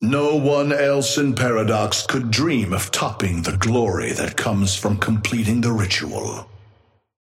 Amber Hand voice line - No one else in Paradox could dream of topping the glory that comes from completing the ritual.
Patron_male_ally_chrono_start_04.mp3